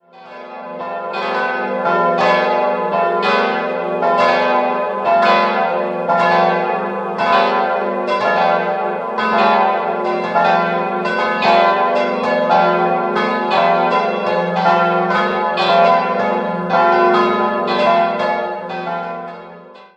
4-stimmiges ausgefülltes F-Dur-Geläute: f'-g'-a'-c'' Die Glocken wurden zwischen 1946 und 1950 von Karl Hamm in Regensburg gegossen.